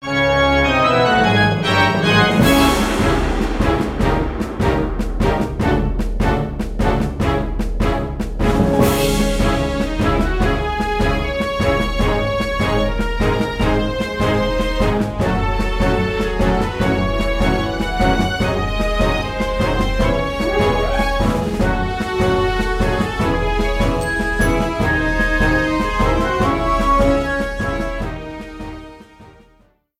Ripped from the ISO
Faded in the end
Reduced length to 30 seconds, with fadeout.